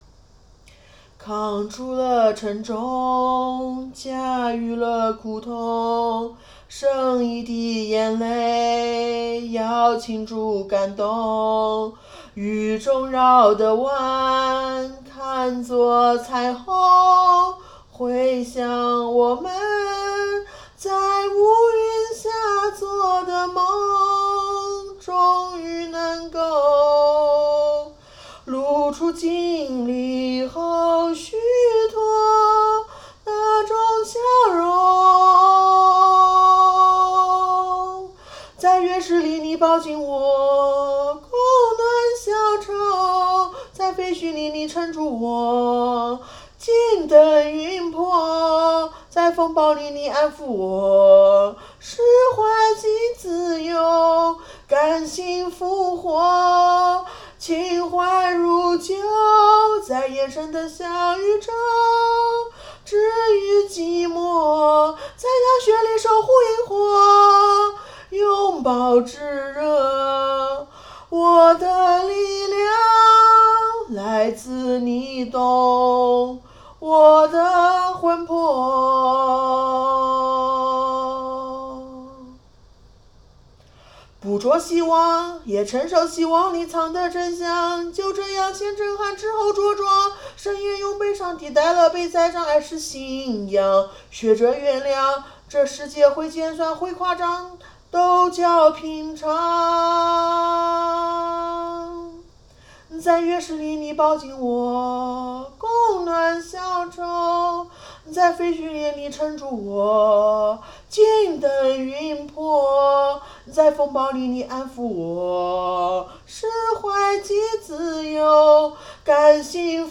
一口气唱完
中途大走调